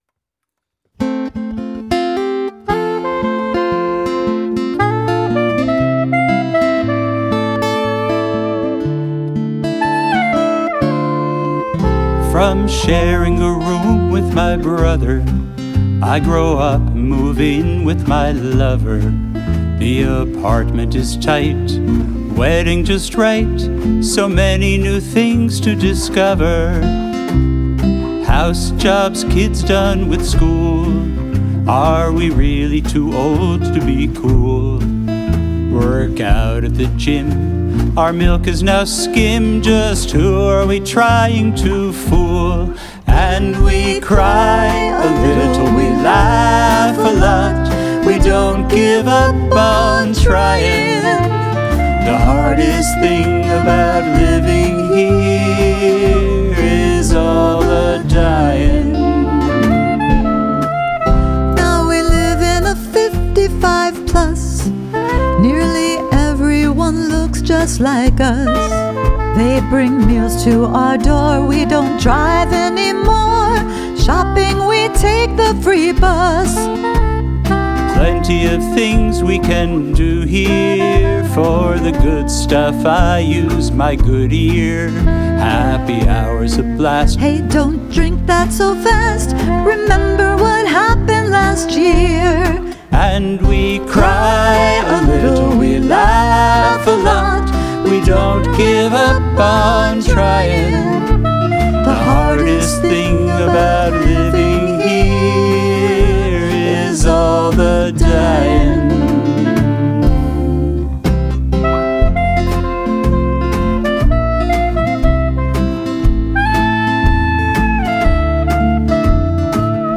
vocals, guitar, kazoo
bass, keyboards, mandolin
drums, djembe, goat hooves, tambourine